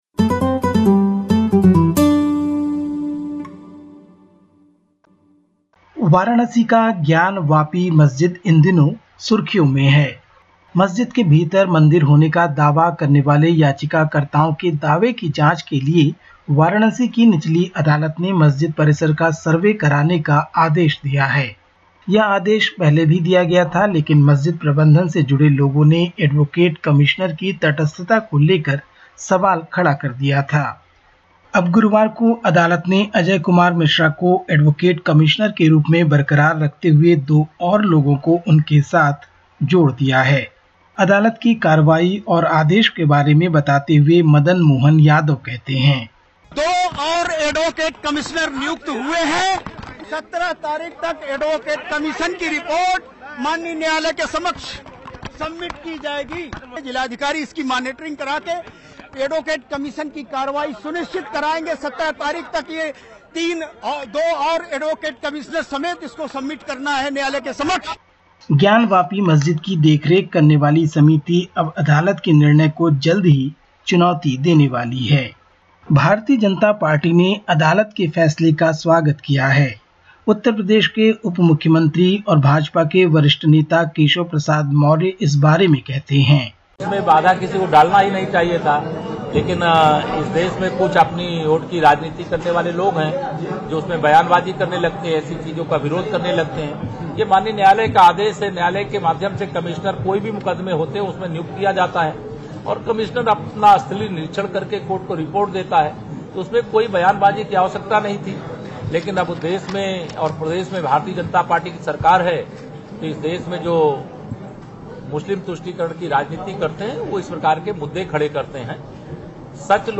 Listen to the latest SBS Hindi report from India. 12/05/2022